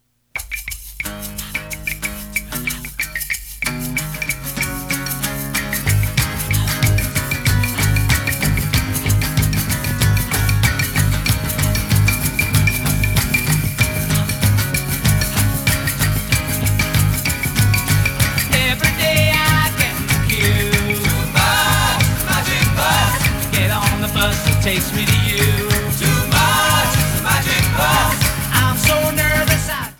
Overall a clean mastering.